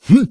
XS蓄力03.wav
XS蓄力03.wav 0:00.00 0:00.32 XS蓄力03.wav WAV · 27 KB · 單聲道 (1ch) 下载文件 本站所有音效均采用 CC0 授权 ，可免费用于商业与个人项目，无需署名。
人声采集素材